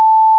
Morse_dash.WAV